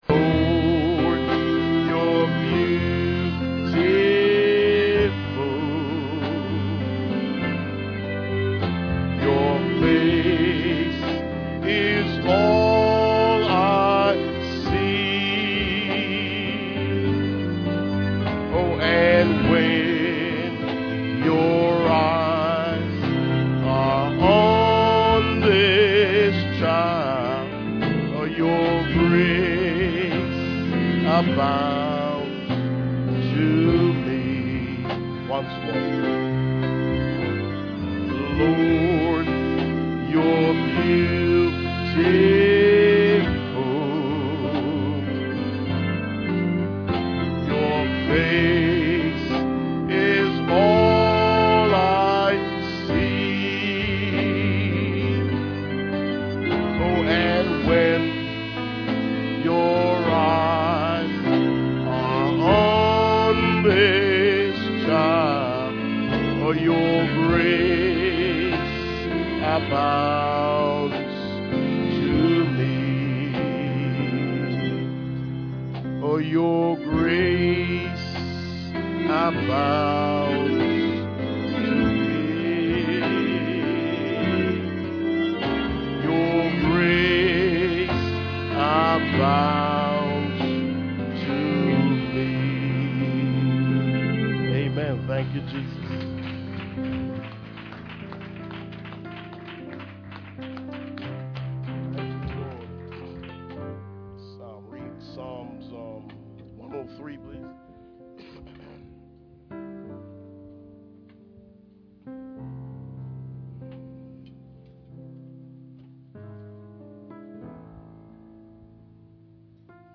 Series: Guest Minister Passage: Matthew 17:1-5, Revelation 10:7 Service Type: Sunday Morning %todo_render% « Revelation Chapter 20